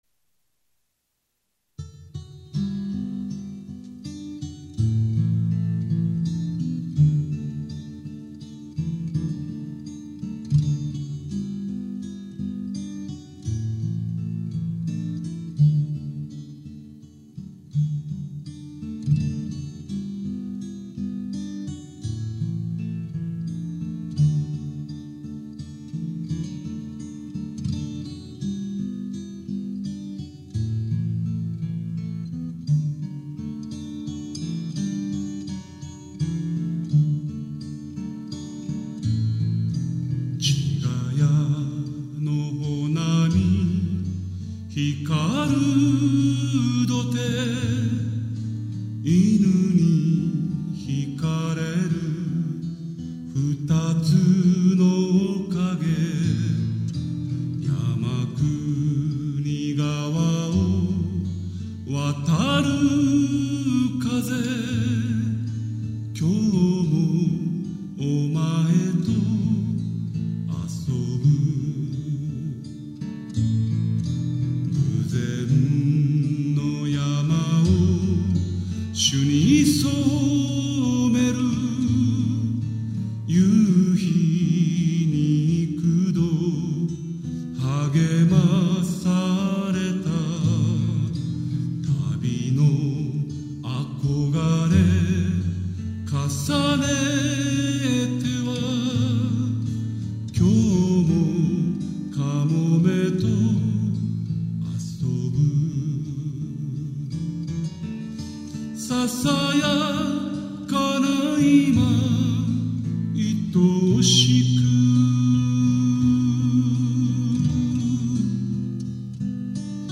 2005年6月18日「第1回　竜一忌」のオープニングで、